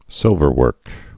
(sĭlvər-wûrk)